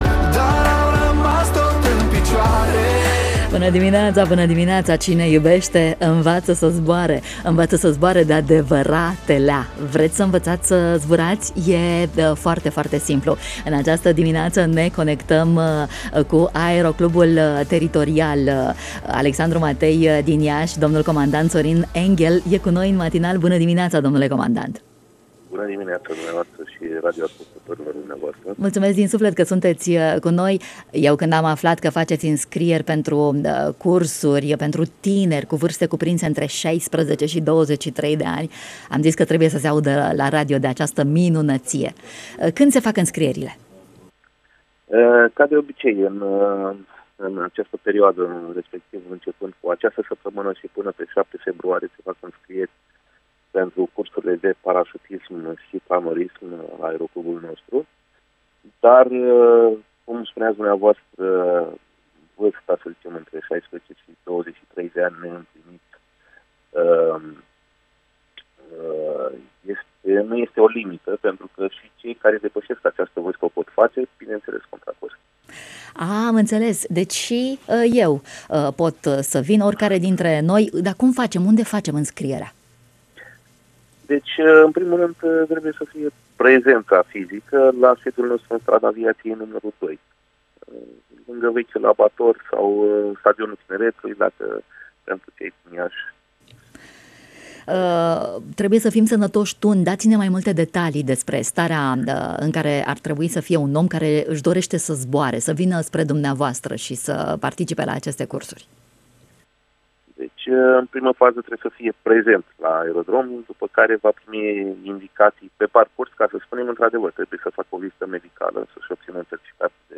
în matinalul Radio România Iaşi.